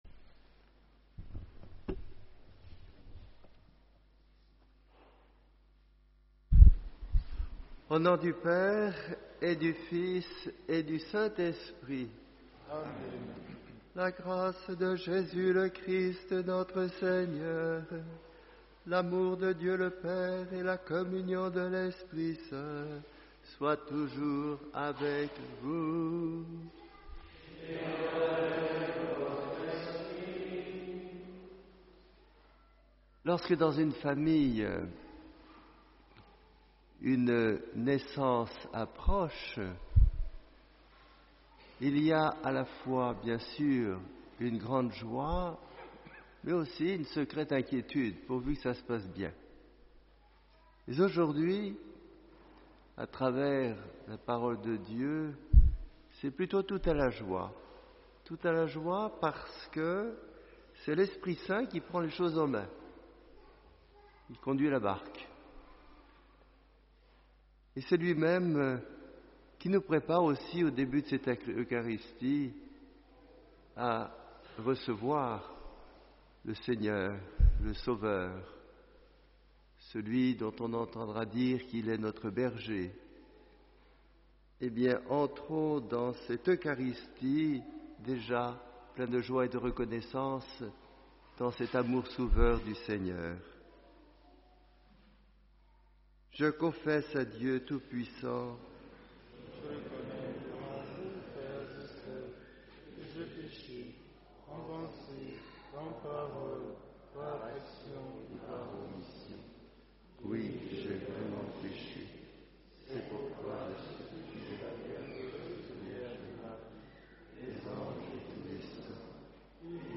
Homélie du 4ème Dimanche Avent C Chers frères et sœurs, Si proche de la Nativité de Jésus, c’est dans 2-3 […]